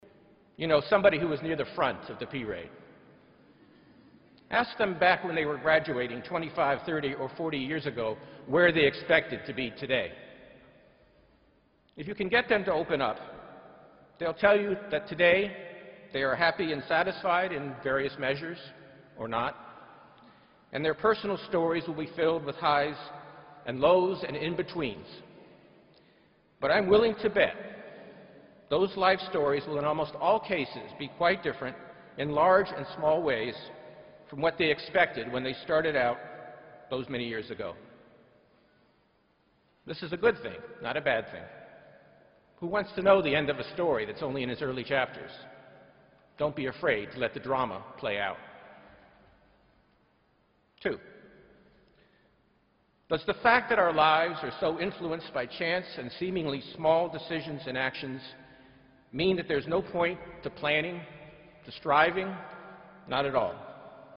公众人物毕业演讲第300期:本伯南克2013普林斯顿(4) 听力文件下载—在线英语听力室